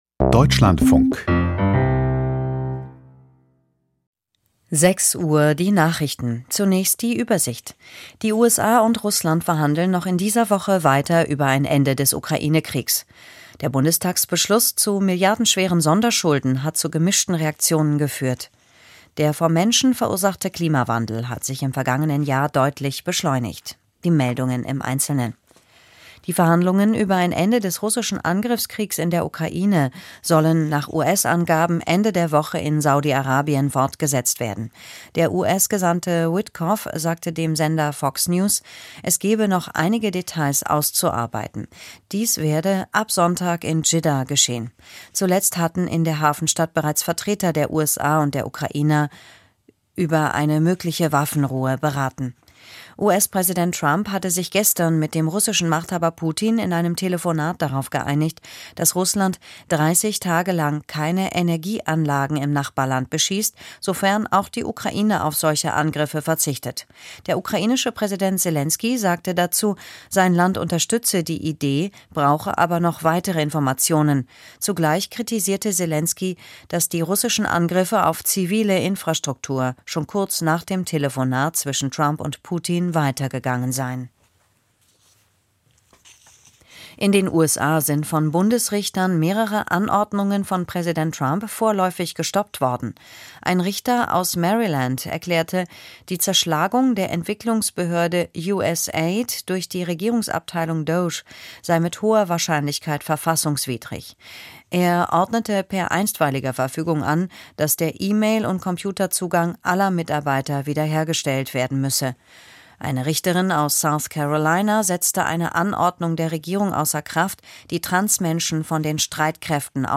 Die Deutschlandfunk-Nachrichten vom 19.03.2025, 05:59 Uhr